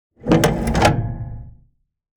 Lawn Mower, Lever Movement 4 Sound Effect Download | Gfx Sounds
Lawn-mower-lever-movement-4.mp3